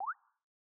Longhorn XP - Balloon.wav